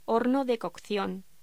Locución: Horno de cocción